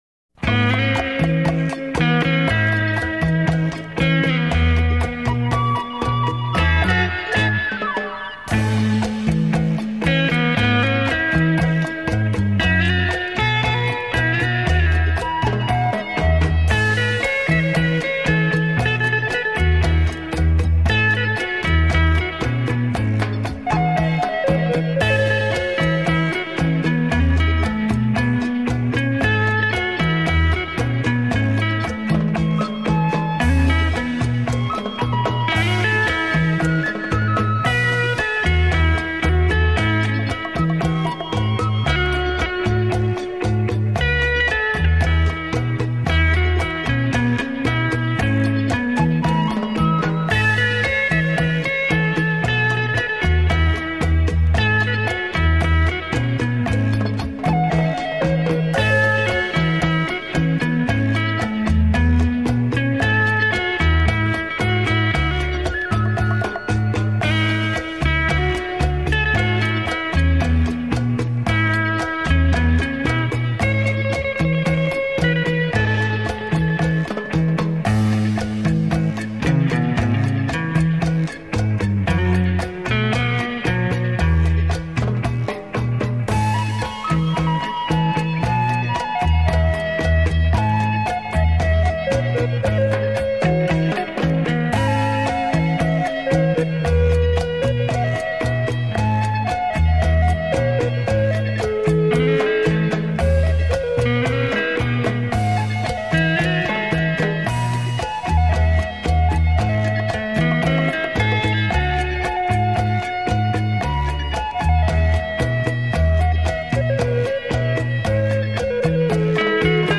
chacha之声
60年代最流行吉打音乐